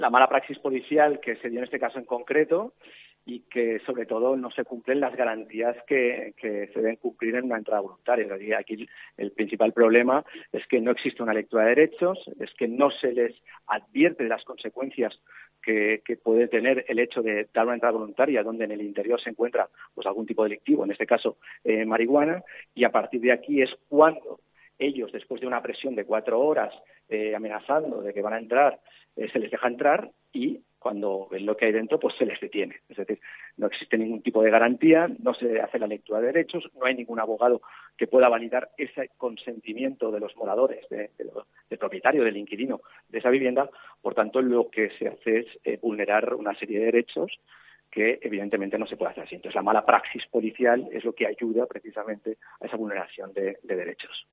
El abogado defensor